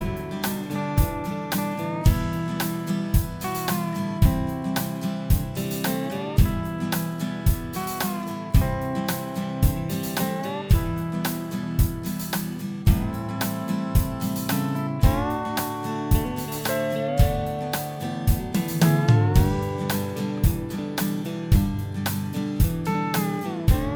Minus Bass Guitar Soft Rock 2:57 Buy £1.50